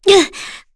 FreyB-Vox_Damage_kr_01.wav